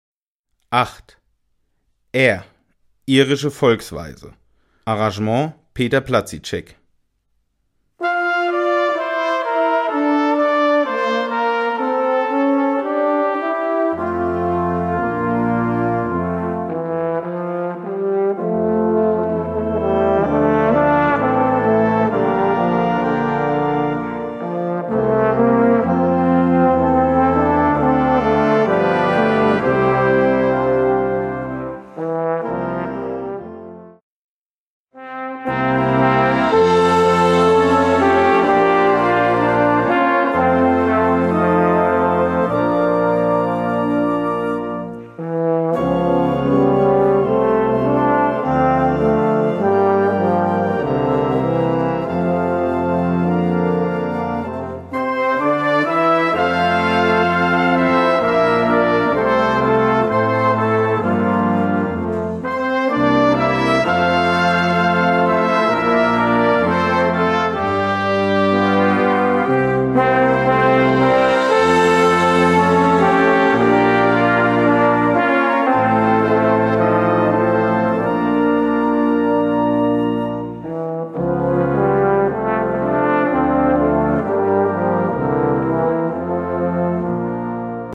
Gattung: Irische Volksweise
Besetzung: Blasorchester
in einem Solo-Arrangement für Posaune / Bariton in Bb/C